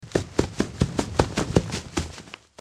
FNaF 1: Running
Category: Games   Right: Personal